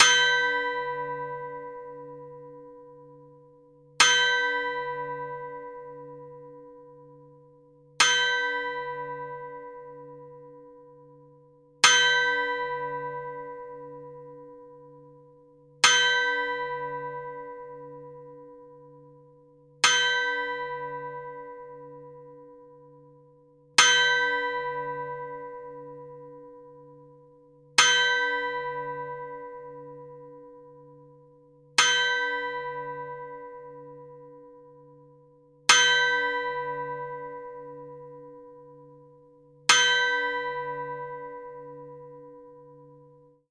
zapsplat_bell_clock_chime_harsh_11428